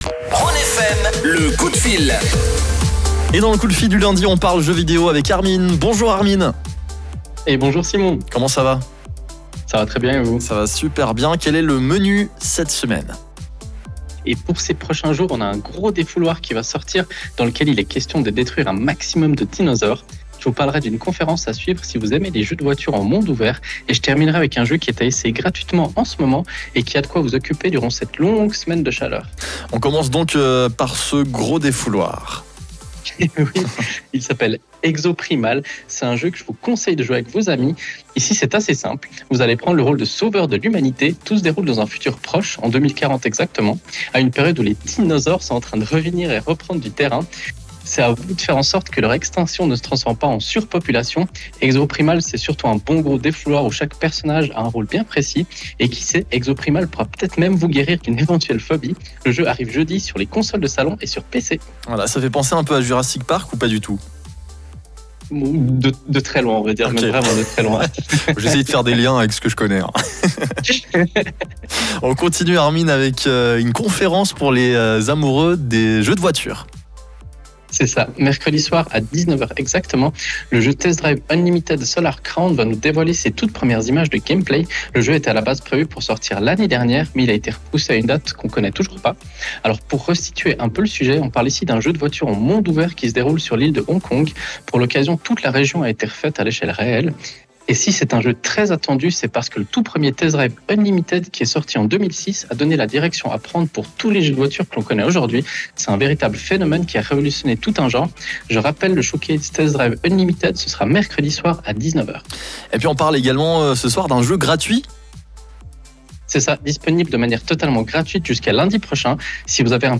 Nous sommes lundi soir, et comme chaque semaine, nous avons la chance de vous proposer notre chronique jeu vidéo sur la radio Rhône FM.